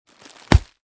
descargar sonido mp3 bolsa crunch